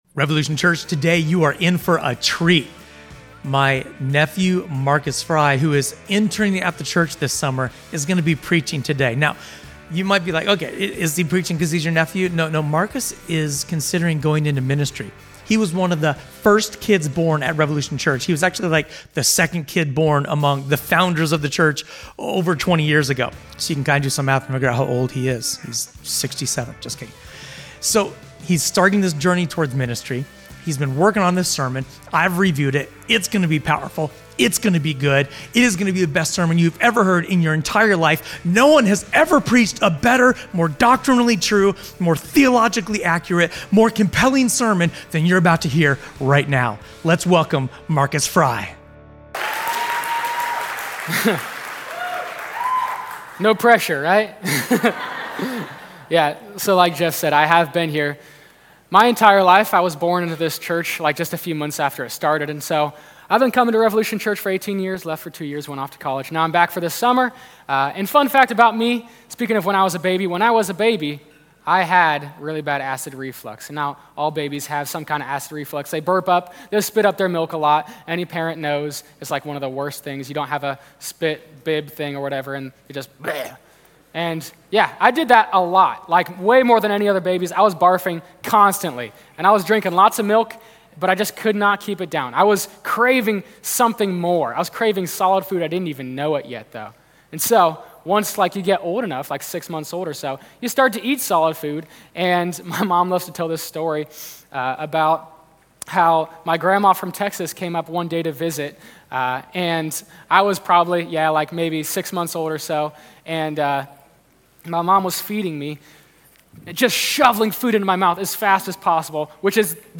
A sermon from the series "Guest."